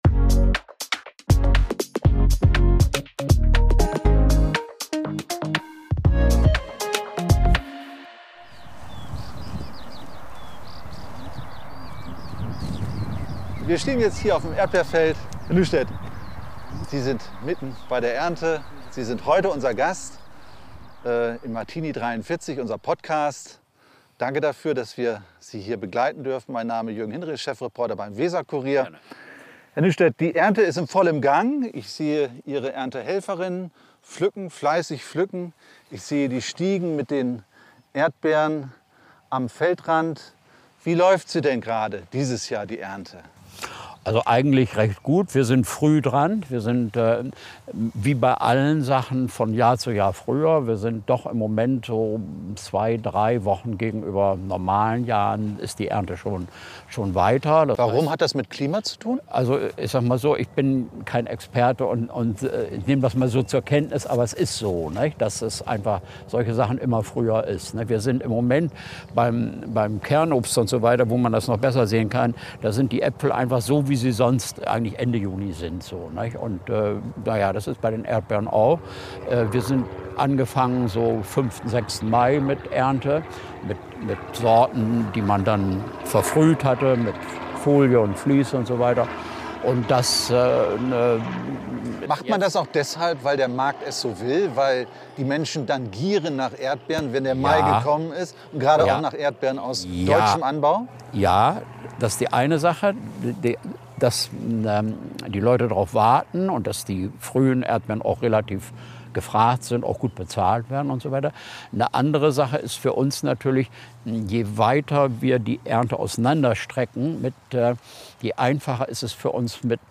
Die günstigen Erdbeeren aus Spanien oder doch die aus dem Bremer Umland? Wer die Antworten hören will, pflücke jetzt Folge 16 von "Martini 43" – ganz frisch vom Erdbeerfeld!